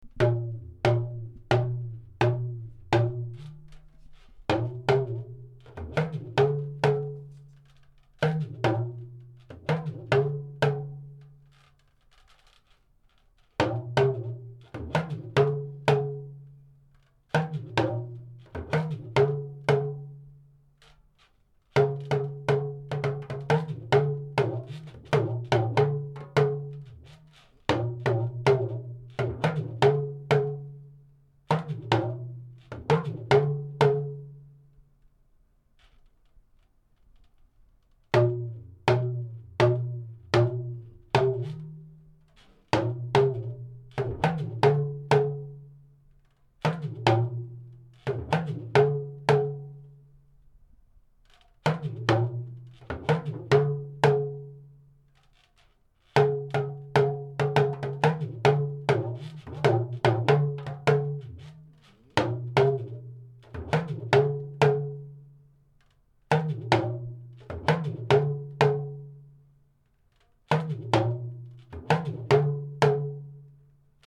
Naawuni Mali Kpam Pam – Dagomba Dance Drumming
After a drummer has shouldered the lunga drum, an invocation is played that serves several purposes.
Time signature: 2/4
Drumming – Audio